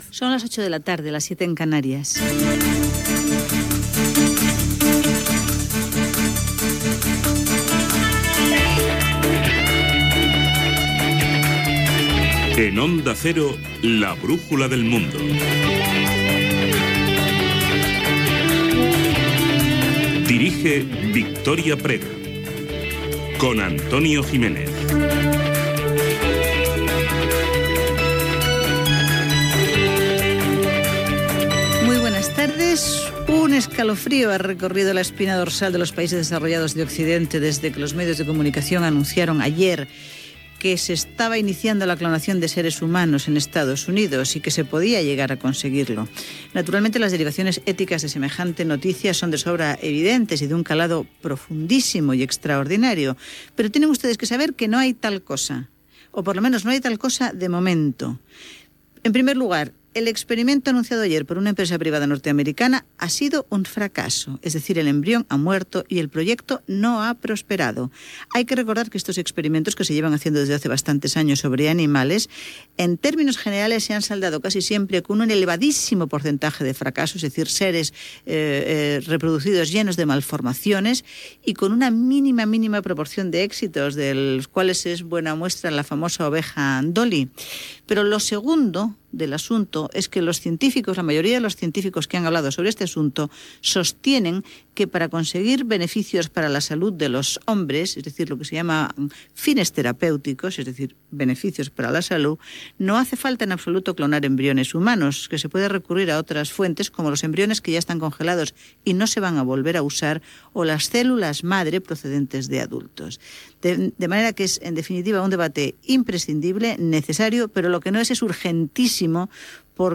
Hora, careta i comentaris sobre la clonació i la guerra d'Afganistan.
Informatiu